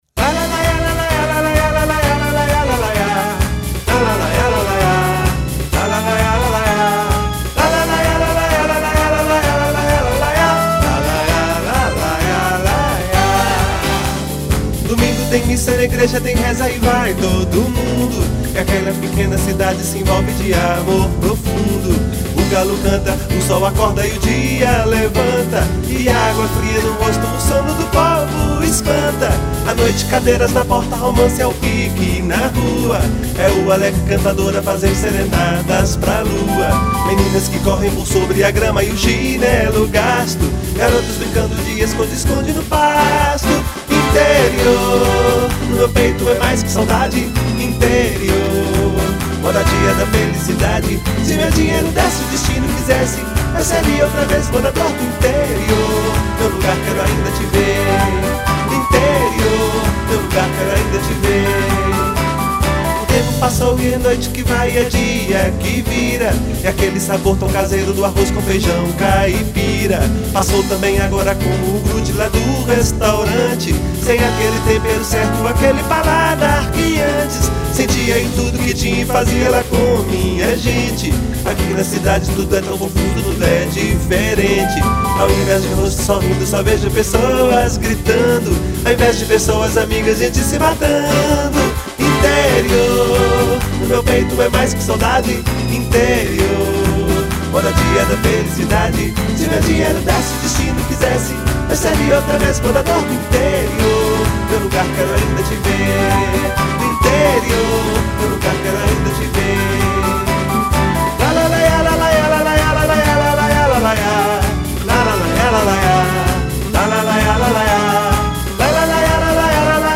Com muito suspense, a vitória foi confirmada; mesmo com uma surpresa; todos achavam que seria com "Interior", a marcha-rancho que falava de nossa cidade e contagiou todos no clube.
Ouça a música Interior, em ritmo de carnaval,  clicando